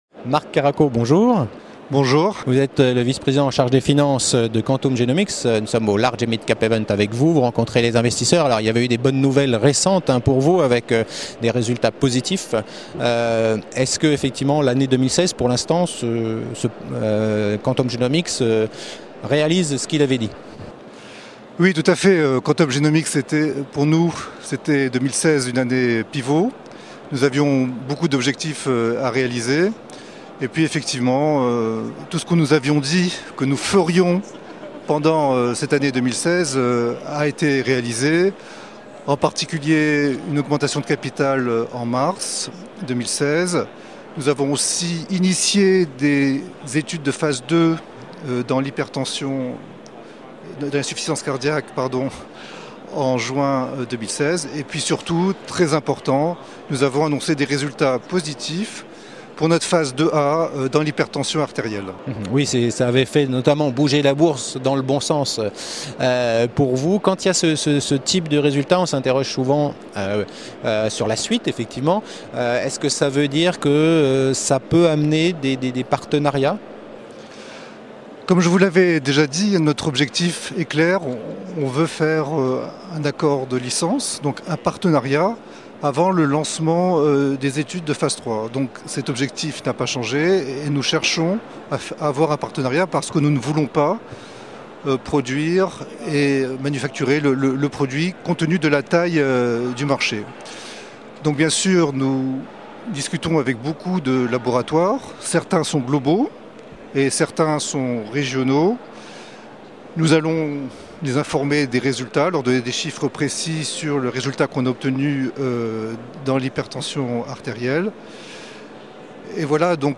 La Web TV au Midcap Event 2016 organisé par CF&B au Palais Brongniart
La Web TV au Midcap Event 2016 organisé par CF&B au Palais Brongniart à la rencontre des dirigeants d’entreprises qui présentent aux investisseurs leurs dernières actualités.